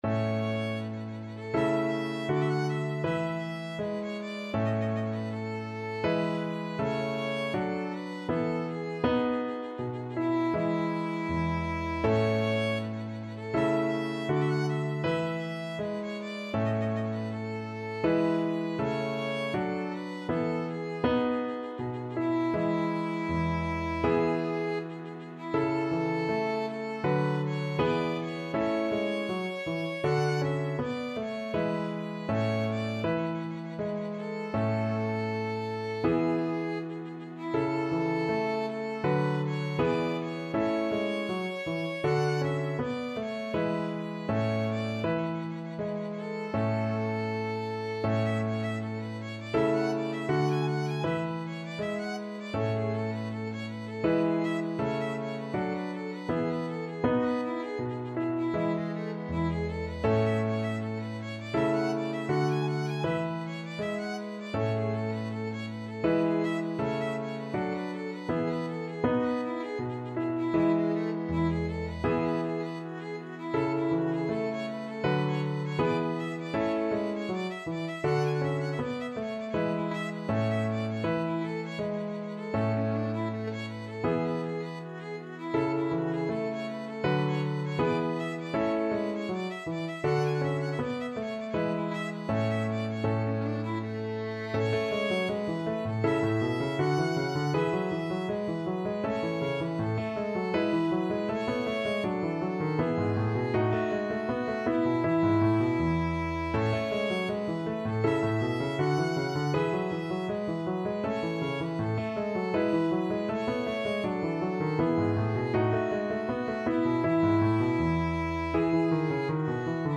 4/4 (View more 4/4 Music)
Allegretto =80
Violin  (View more Advanced Violin Music)
Classical (View more Classical Violin Music)